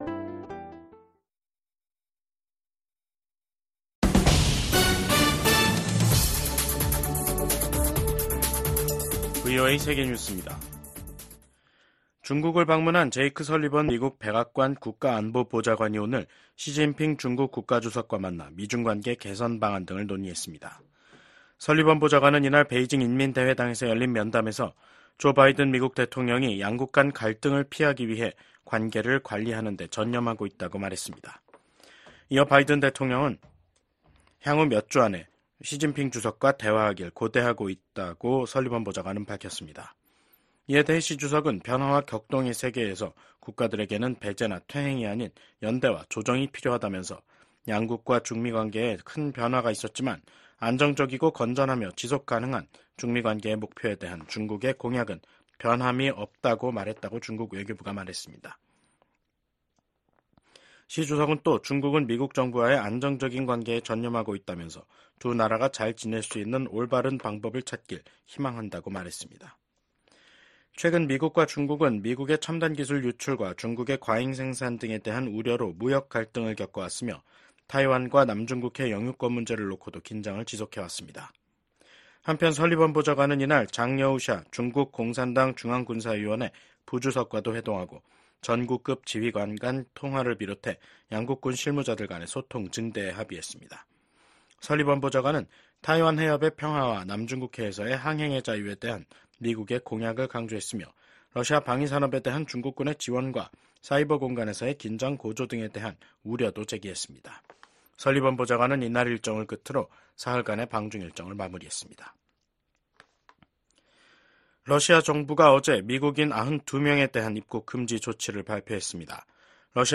VOA 한국어 간판 뉴스 프로그램 '뉴스 투데이', 2024년 8월 29일 2부 방송입니다. 미국 국무부는 북한의 잠수함 국제해사기구(IMO)에 등록 사실을 인지하고 있다며 북한의 불법 무기프로그램을 규탄한다고 밝혔습니다. 미국 국가안보보좌관이 중국 외교부장과 만나 다양한 현안에 대한 솔직하고 건설적인 대화를 나눴다고 백악관이 밝혔습니다. 윤석열 한국 대통령은 29일 미한일 정상의 캠프 데이비드 협력은 지도자가 바뀌더라도 변하지 않을 것이라고 밝혔습니다.